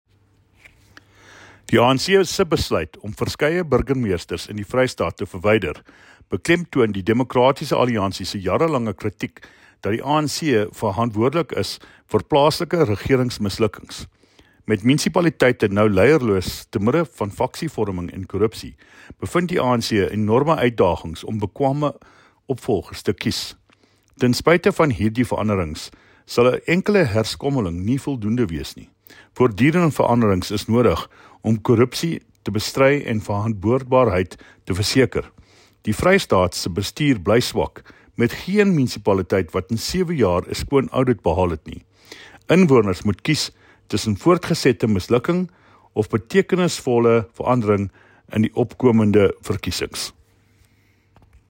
Afrikaans soundbites by David Mc Kay MPL and